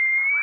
radio_random3.ogg